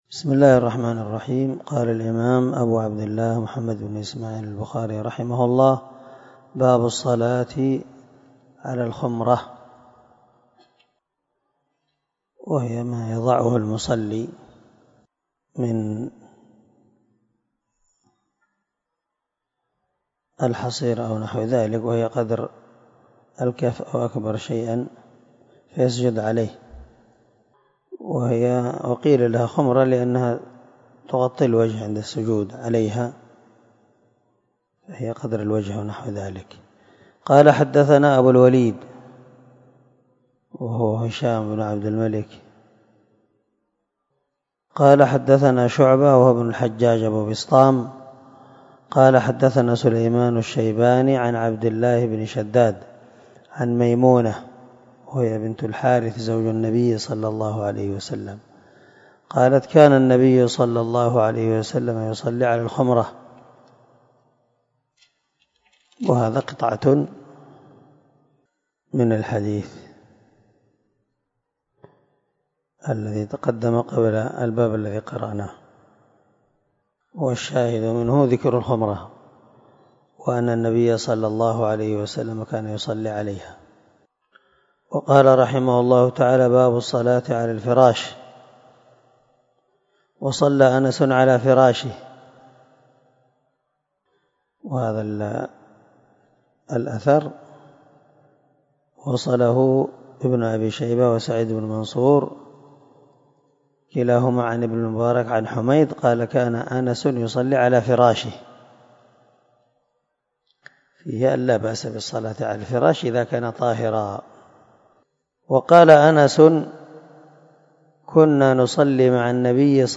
294الدرس 27 من شرح كتاب الصلاة حديث رقم ( 381 - 384 ) من صحيح البخاري